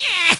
tnt_guy_hurt_04.ogg